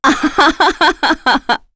Epis-Vox_Happy2.wav